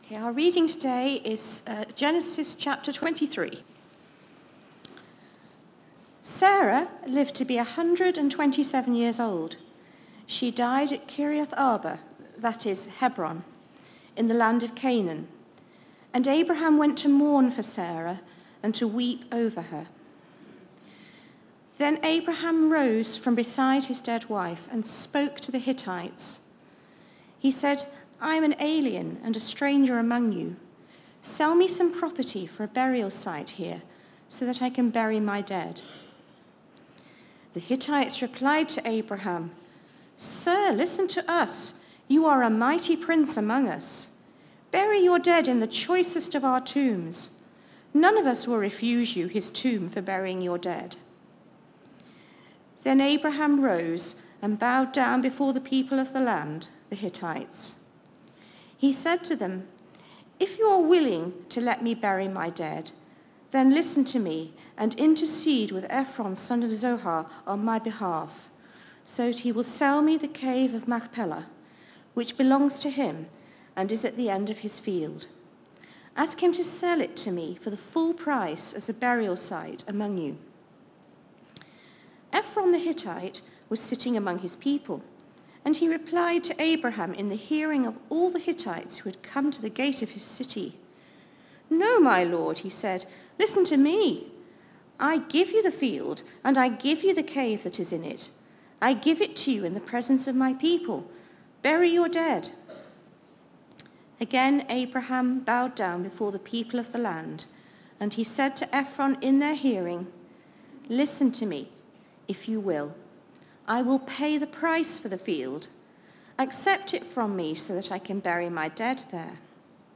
Media for Sunday Service on Sun 26th Jan 2025 10:00